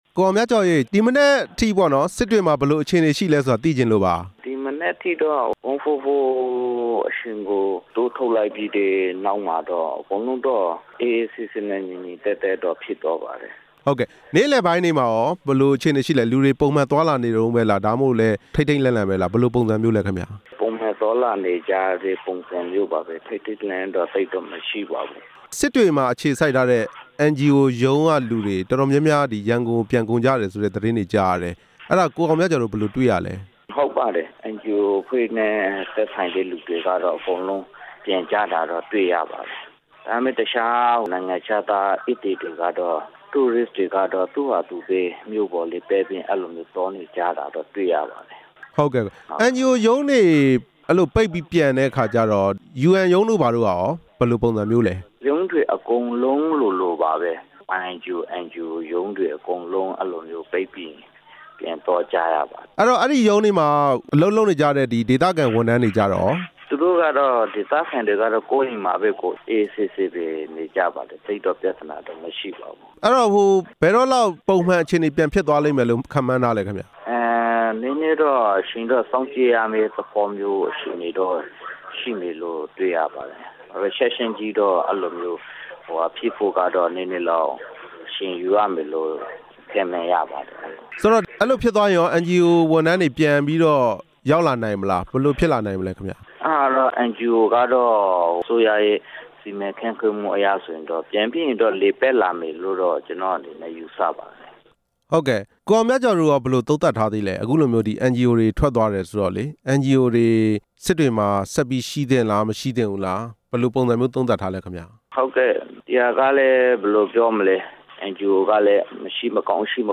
ရခိုင်ပြည်နယ်က NGO တွေရဲ့ အခြေအနေ မေးမြန်းချက်